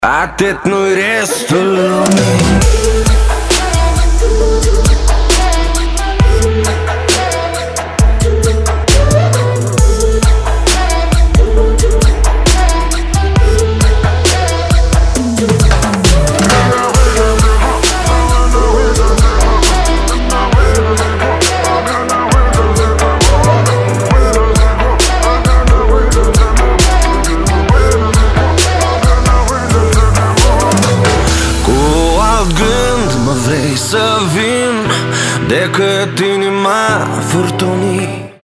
поп
dance
Electronic
Bass